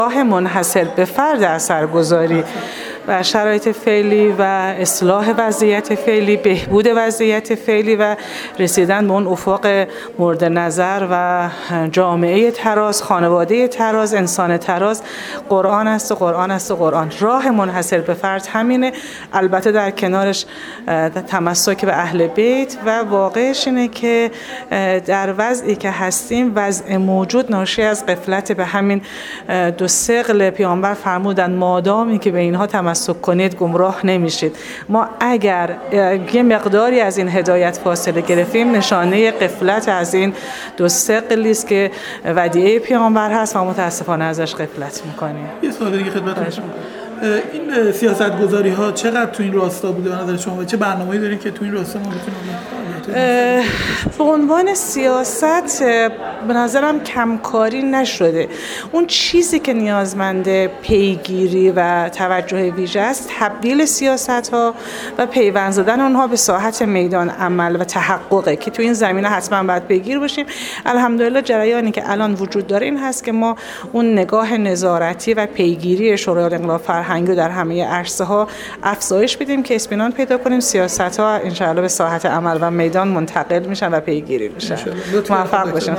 فهیمه فرهمند‌پور، رئیس شورای فرهنگی، اجتماعی خانواده و زنان شورای عالی انقلاب فرهنگی، در گفت‌وگو با ایکنا، در پاسخ به سؤالی درباره جایگاه فعالیت‌های قرآنی در اصلاح سبک زندگی و کاهش آسیب‌ها گفت: راه منحصر به فرد در شرایط فعلی و اصلاح و بهبود این وضعیت و رسیدن به افق مورد نظر جامعه، خانواده و انسان تراز، قطعا قرآن کریم و آموزه‌های الهی آن است.